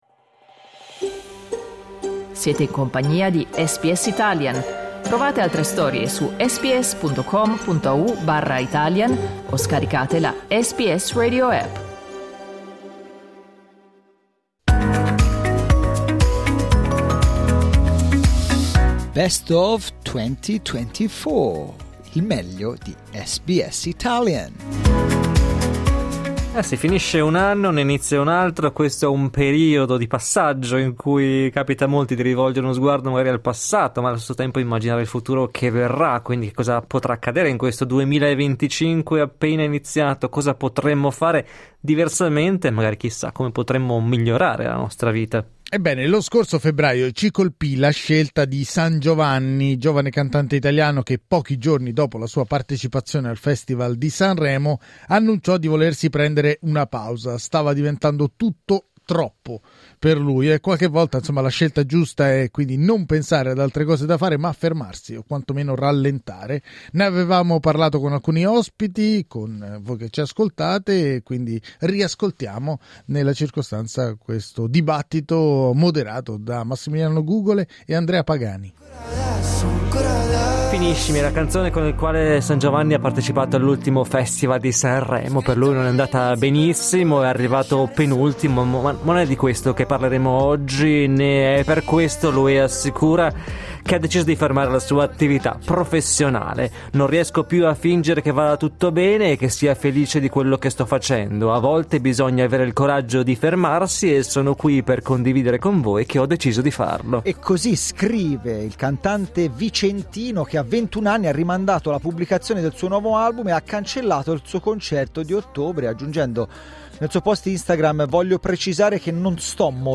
Dai nostri archivi, un dibattito ispirato dalla decisione del cantautore Sangiovanni, maturata dopo il Festival di Sanremo del 2024: mettere in pausa la sua carriera musicale per concentrarsi sulla sua salute mentale.